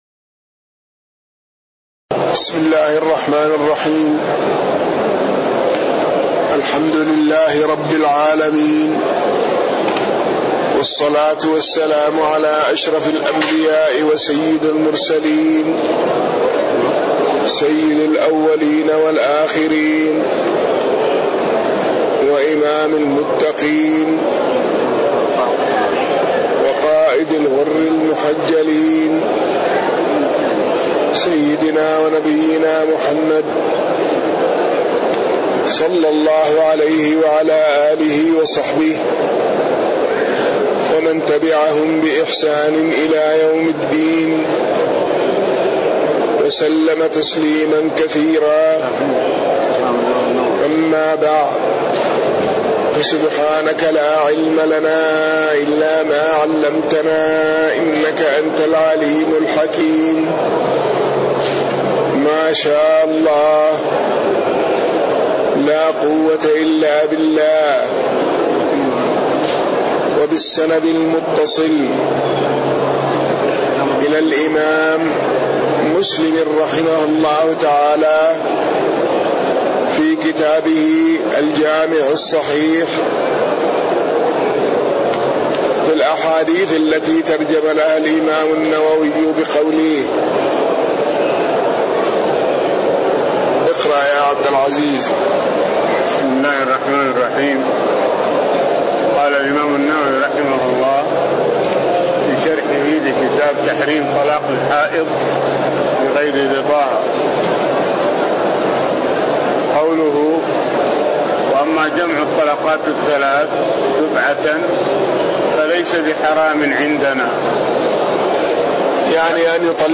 تاريخ النشر ١٥ شعبان ١٤٣٤ هـ المكان: المسجد النبوي الشيخ